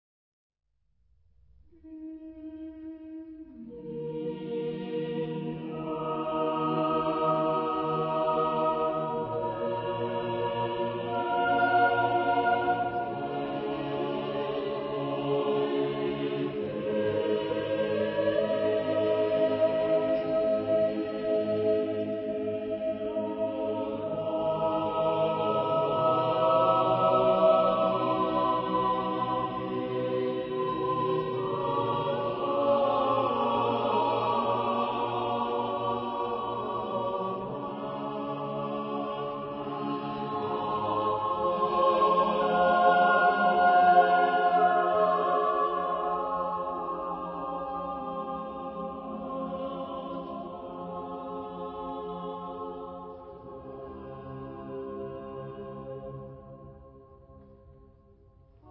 Genre-Style-Forme : Sacré ; Baroque ; Motet
Type de choeur : SSATTB OU SSTTTB  (6 voix mixtes )